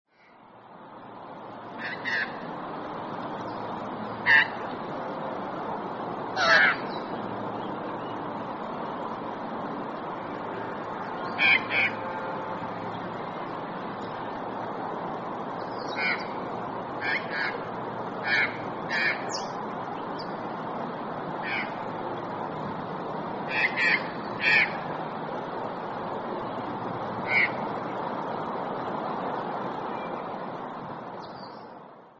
Australian Magpie - Gymnorhina tibicen
Voice: mellow, musical carolling, sometimes at night; short, harsh alarm call; less harsh squawk.
Call 2: short, harsh call
Aus_Magpie_2_squawk.mp3